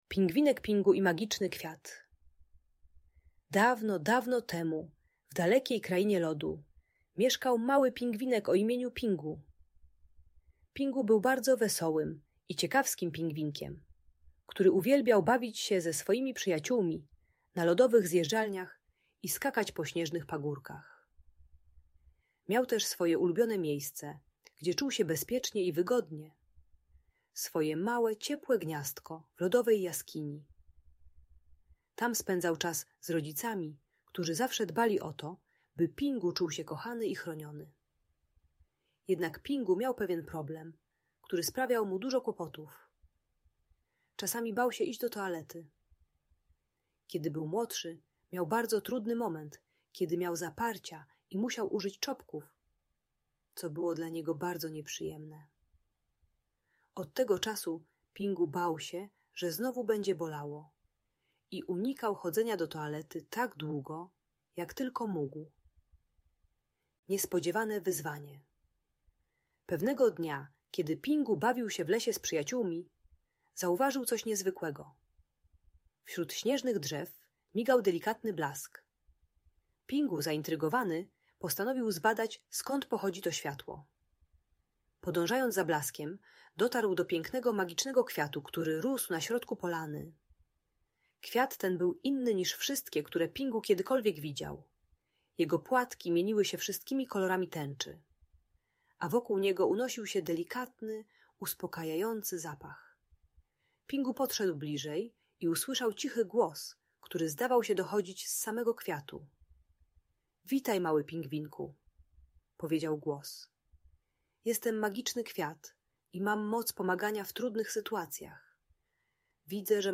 Bajka dla dziecka które boi się chodzić do toalety i wstrzymuje kupę.